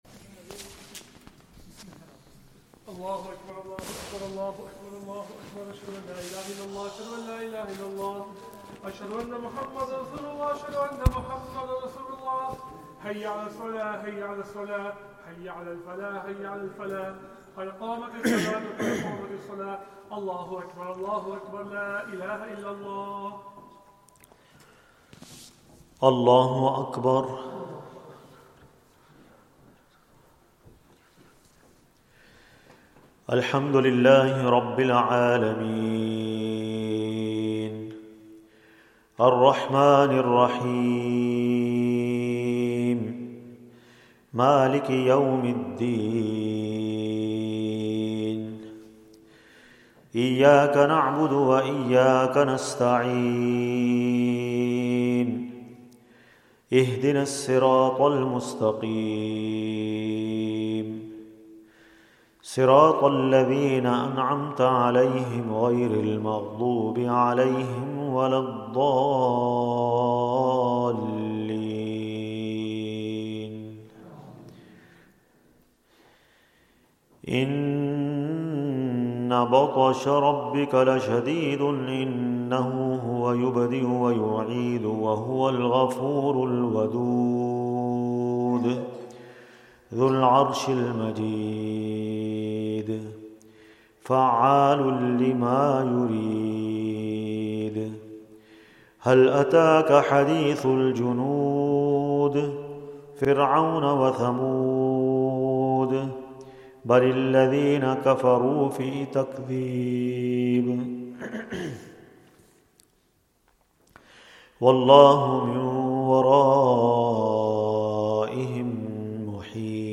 Zakariyya Masjid Motherwell | Taraweeh | eMasjid Live
Taraweeh